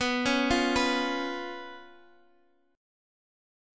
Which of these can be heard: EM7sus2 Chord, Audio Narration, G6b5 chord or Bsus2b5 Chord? Bsus2b5 Chord